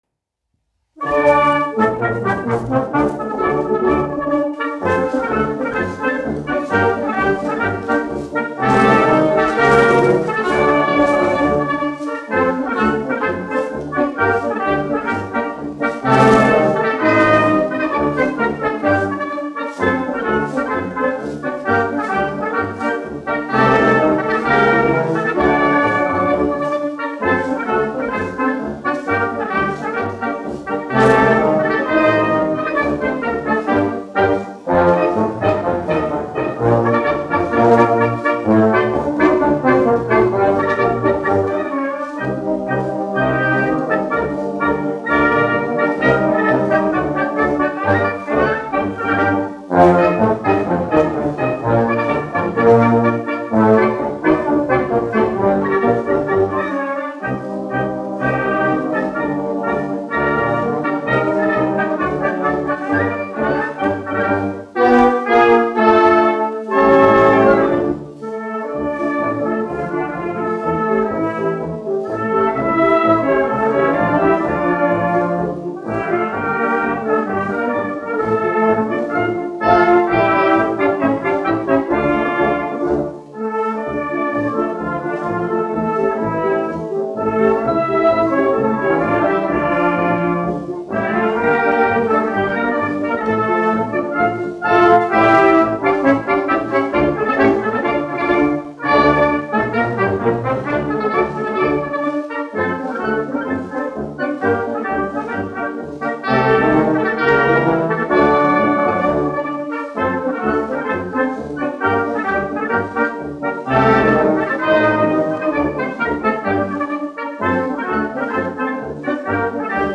1 skpl. : analogs, 78 apgr/min, mono ; 25 cm
Marši
Pūtēju orķestra mūzika
Skaņuplate